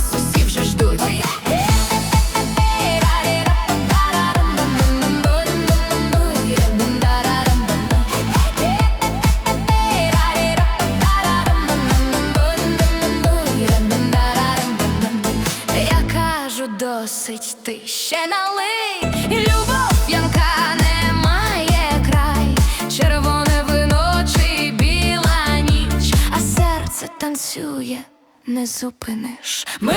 Жанр: Поп музыка / Рок / Фолк / Украинские